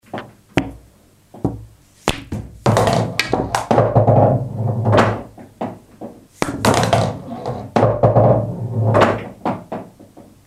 Snooker
potting-snooker-balls.mp3